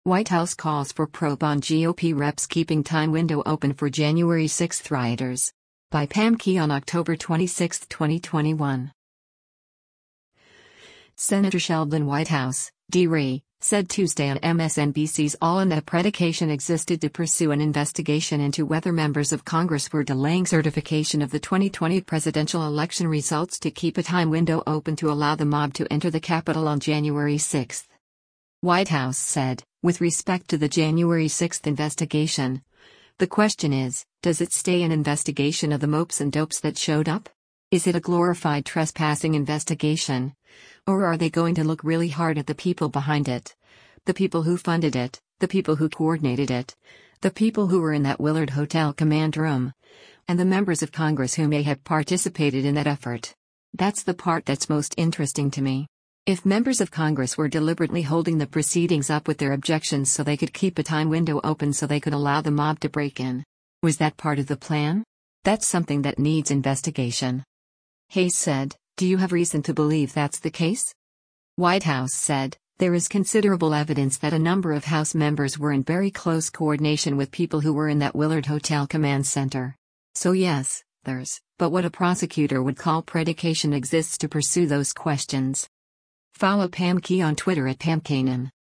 Senator Sheldon Whitehouse (D-RI) said Tuesday on MSNBC’s “All In” that a “predication” existed to pursue an investigation into whether members of Congress were delaying certification of the 2020 presidential election results to keep a “time window open” to allow the mob to enter the Capitol on January 6.